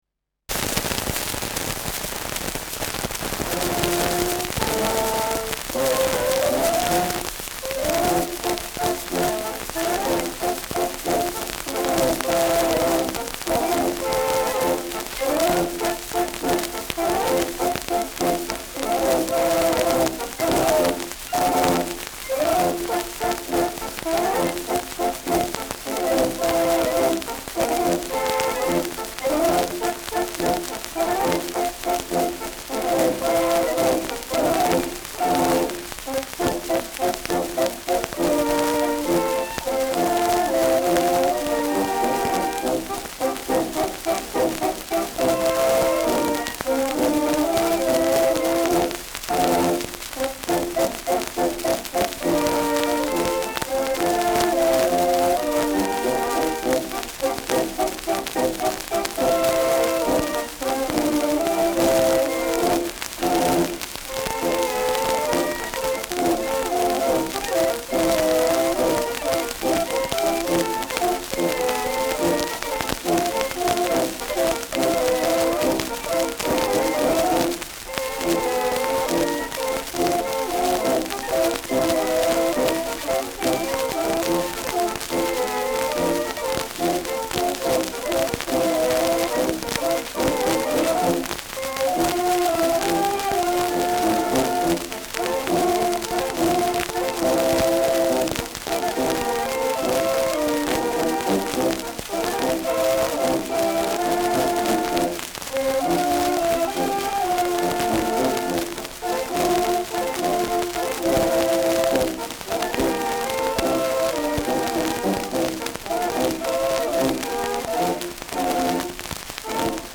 Schellackplatte
starkes Rauschen : Knacken durchgängig : leiert : starkes Knistern
Tupfentaler Bauernmusik (Interpretation)